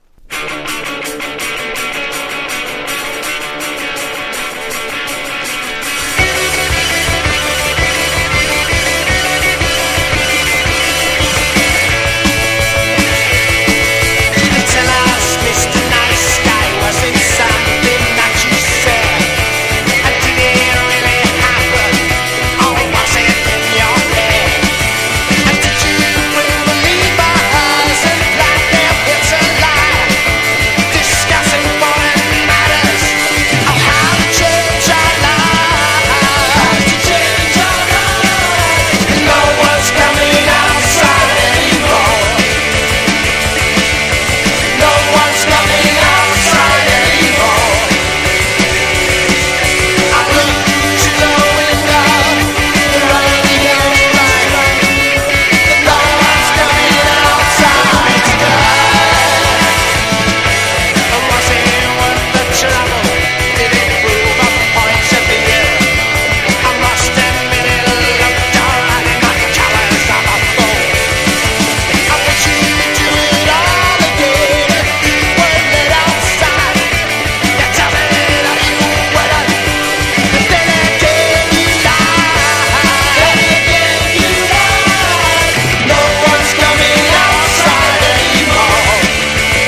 初期PUNK / POWER POP